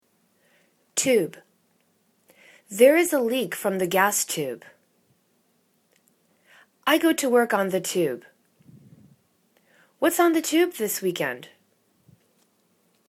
tube /tu:b/ n